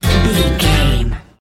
Ionian/Major
banjo
violin
double bass
acoustic guitar